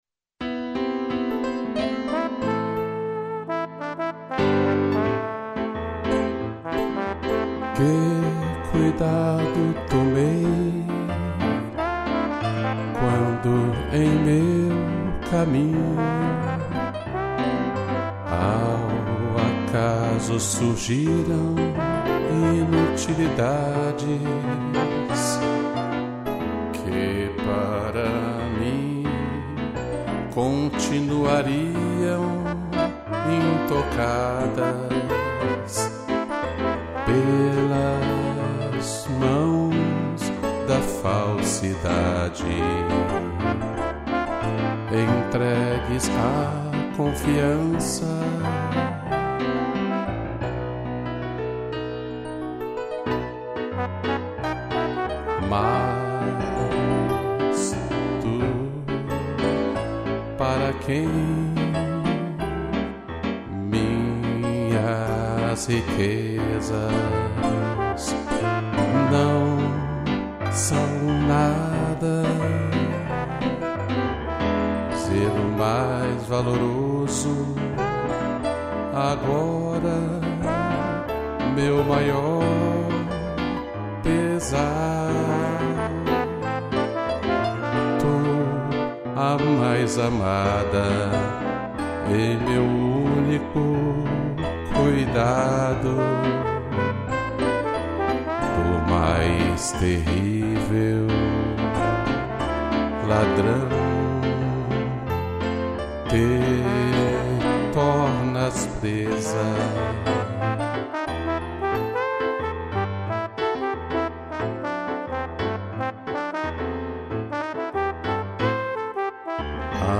2 pianos e trombone